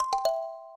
kalimba_c1ge.ogg